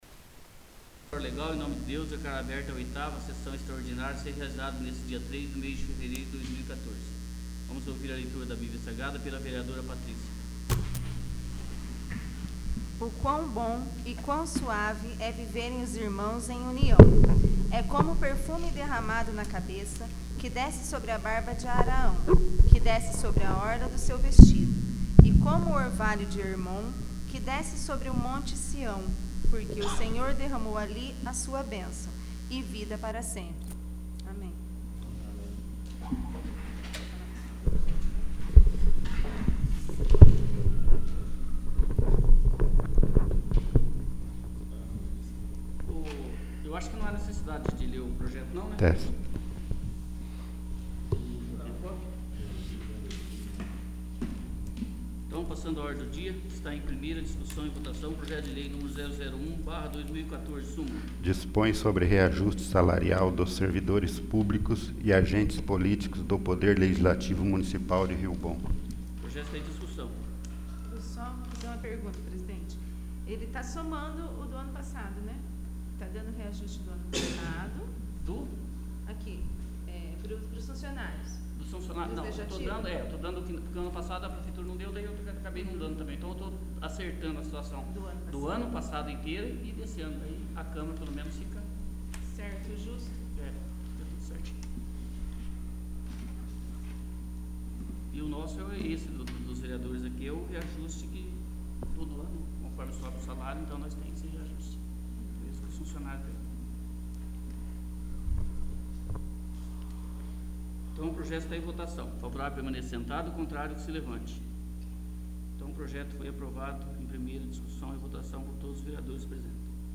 8º. Sessão Extraordinária